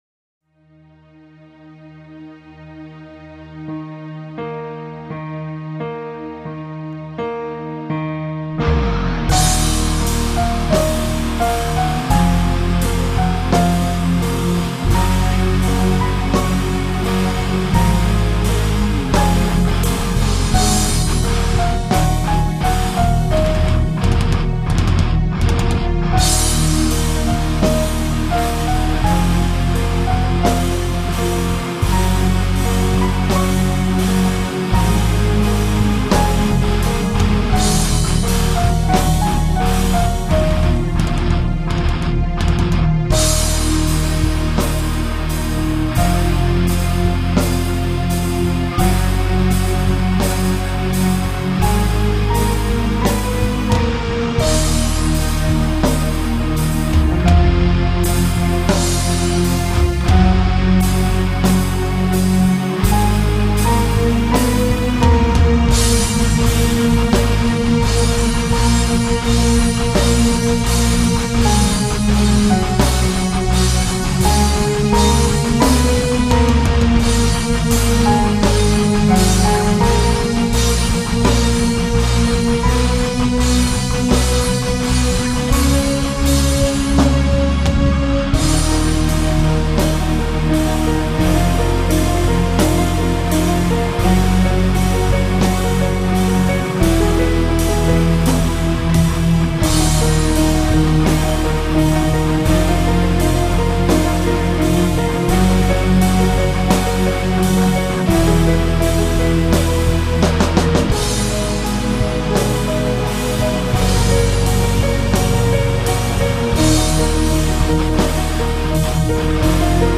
готика